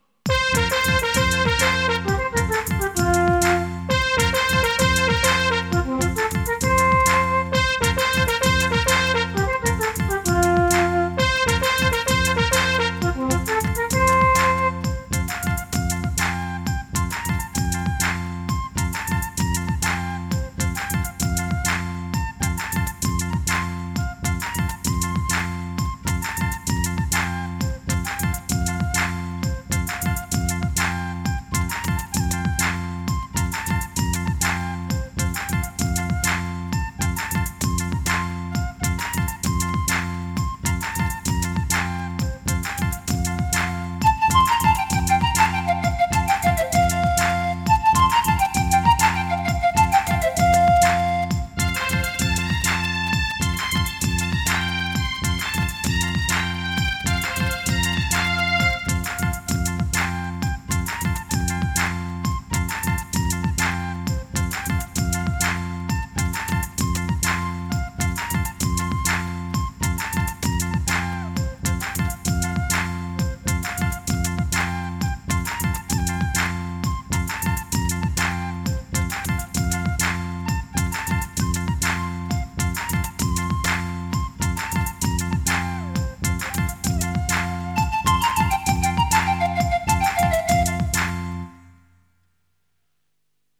Minus One Tracks